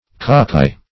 cockeye - definition of cockeye - synonyms, pronunciation, spelling from Free Dictionary